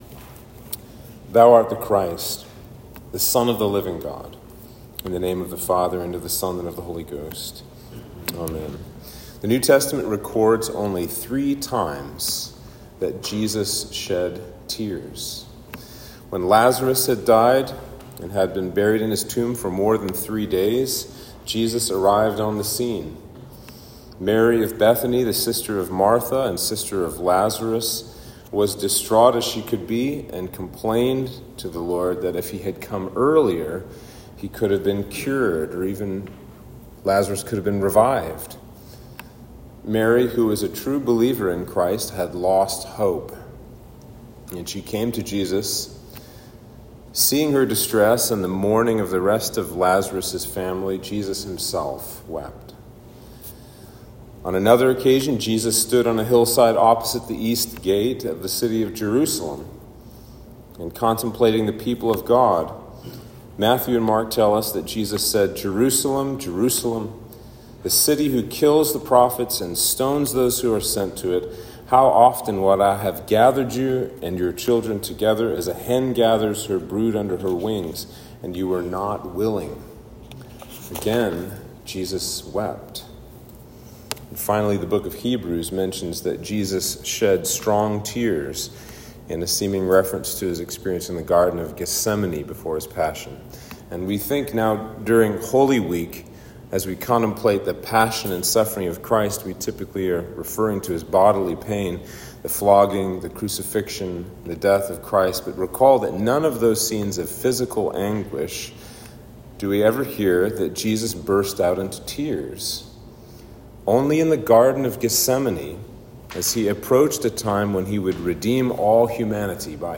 Sermon for St. Peter's Day - June 29